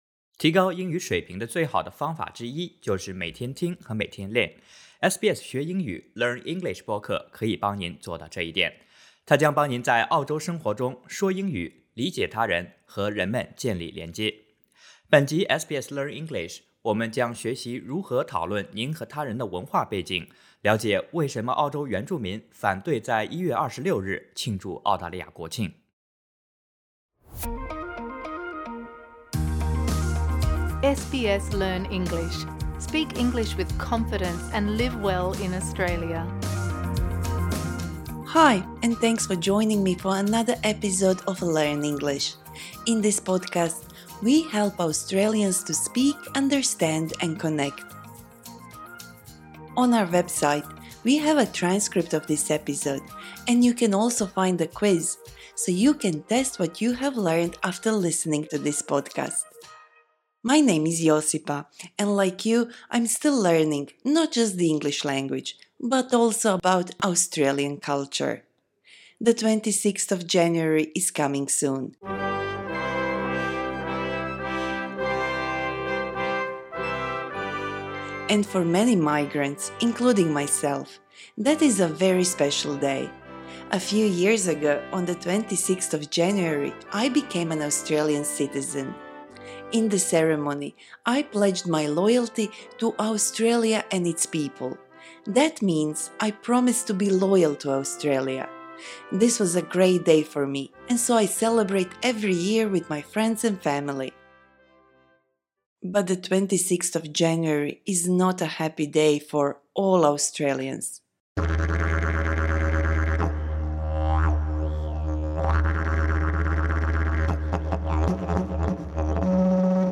Learn English Podcast Ep3 Source: SBS